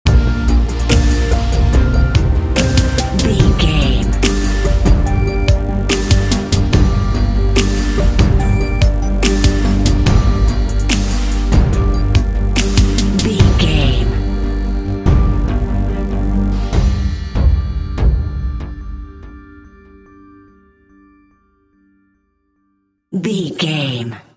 Thriller
Aeolian/Minor
synthesiser
drums
strings
contemporary underscore